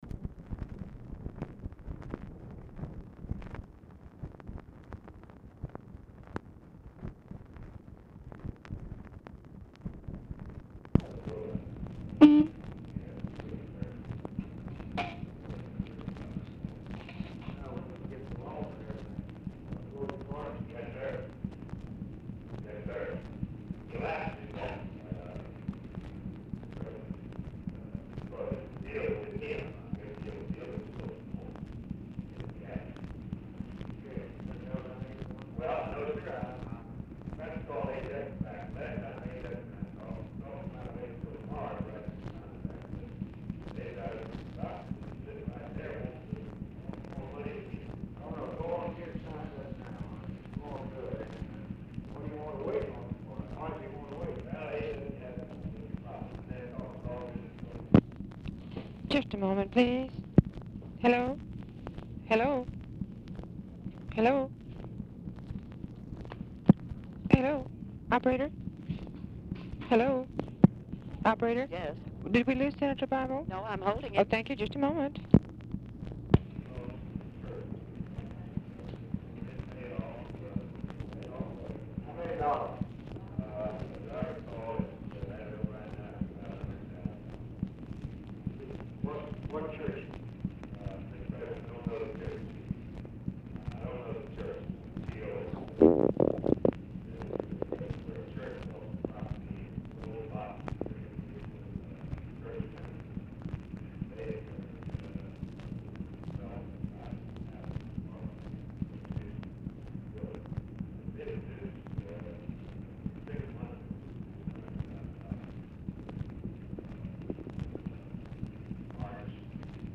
Telephone conversation # 9036, sound recording, LBJ and ALAN BIBLE, 10/22/1965, 6:30PM | Discover LBJ
Format Dictation belt
Oval Office or unknown location
Specific Item Type Telephone conversation